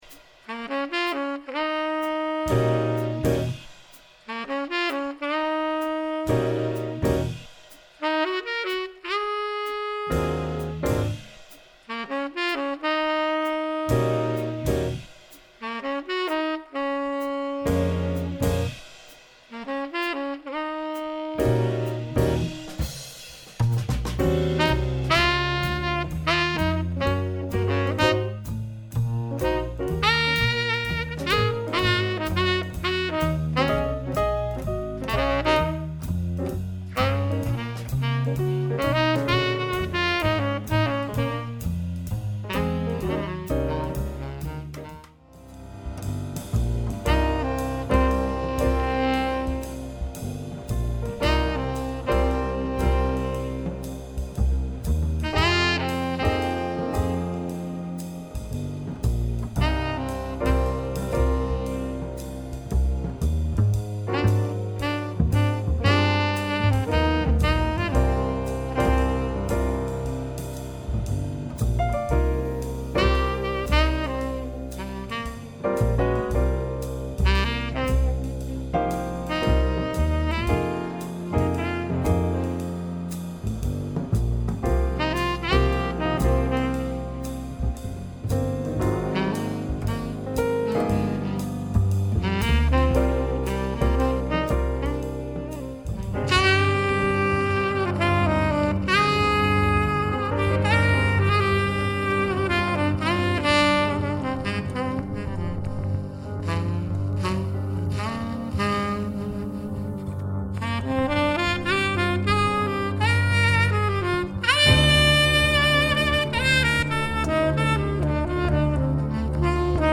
Incomum e requintado.
tenor and soprano sax
coltrane-tribute.mp3